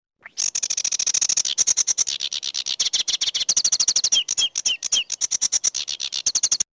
Categories: Animal SoundsBird Sounds
Sedge Warbler Bird Sound
Tags: Animal Sound